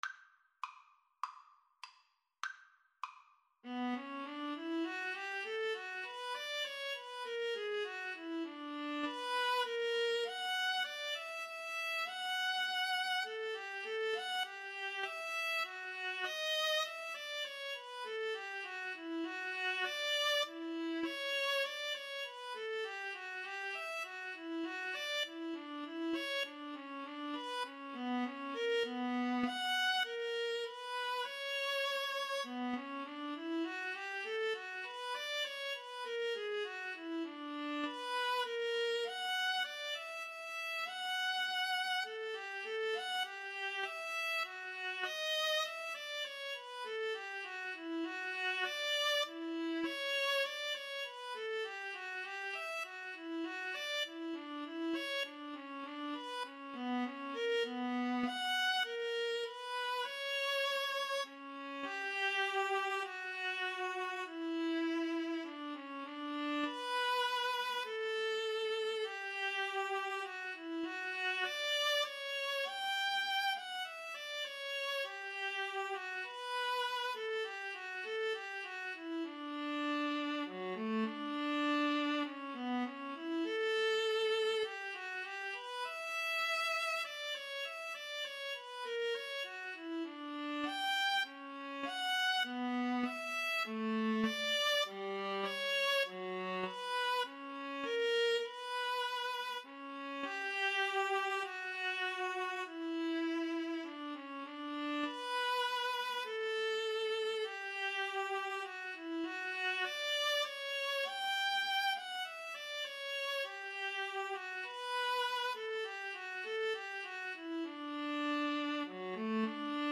B minor (Sounding Pitch) (View more B minor Music for Viola Duet )
2/2 (View more 2/2 Music)
Viola Duet  (View more Intermediate Viola Duet Music)
Classical (View more Classical Viola Duet Music)